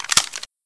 pistol_reload1.ogg